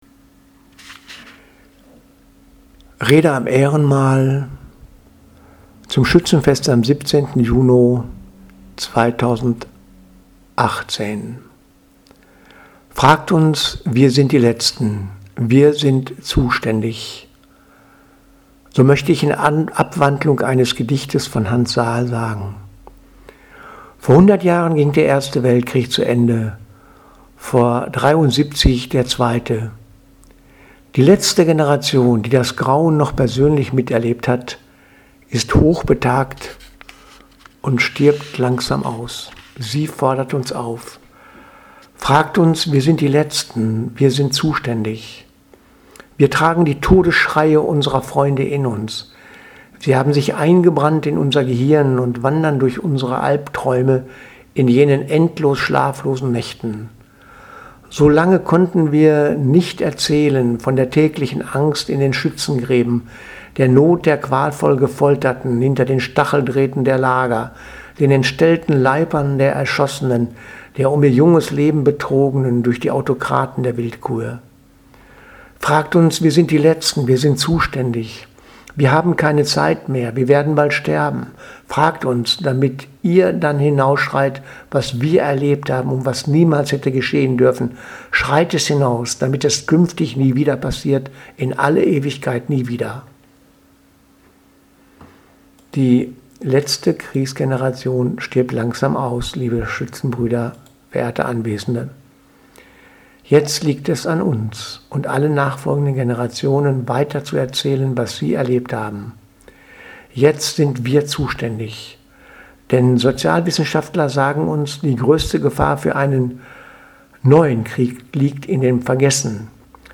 Predigt vom 17.06.2018 Rede am Ehrenmal
2018-06-17_Rede_am Ehrenmal_zum Schützenfest